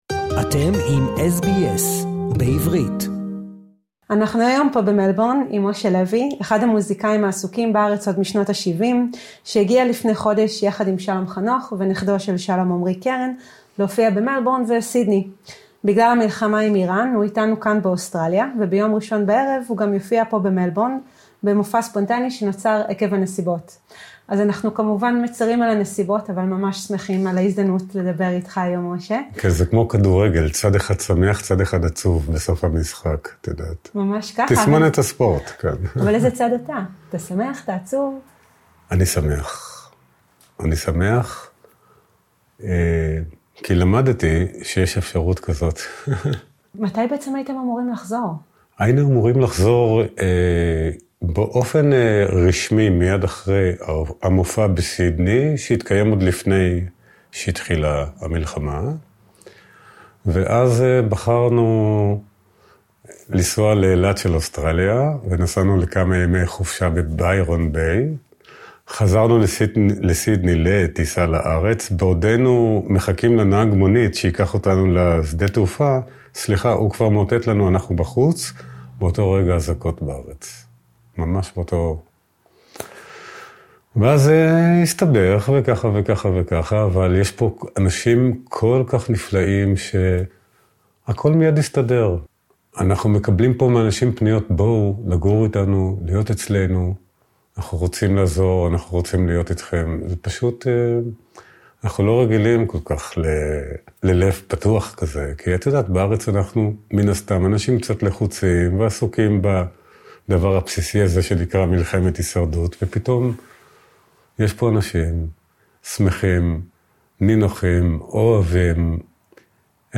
בראיון מיוחד ל-SBS